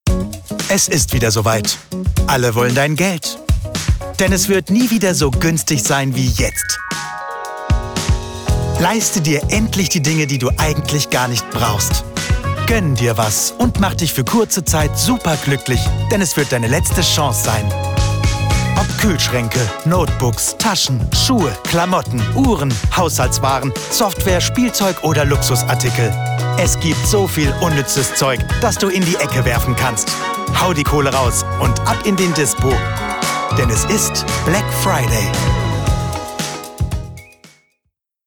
High professional German voice talent: Charming, Emotional, Happy, Hip, Optimistic, Bold, Calm, Charismatic, Cool, Emotional, Motivational
Sprechprobe: Werbung (Muttersprache):